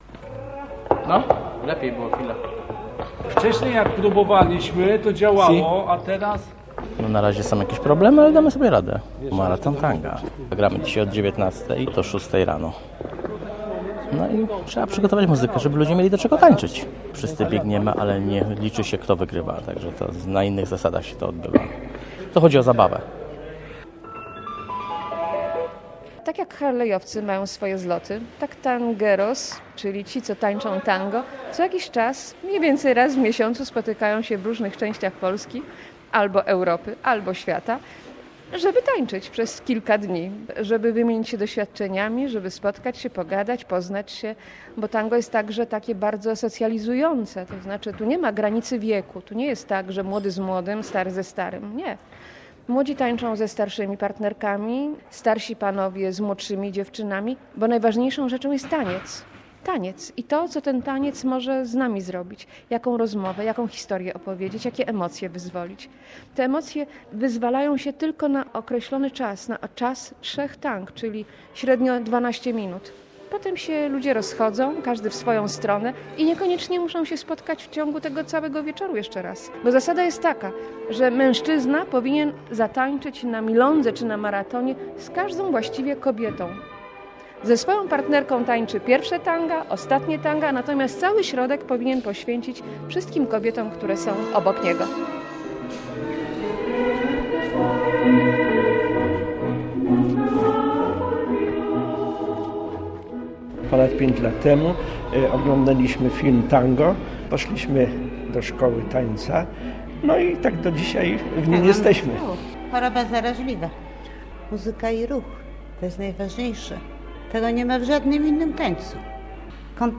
Dalekie i bliskie - reportaż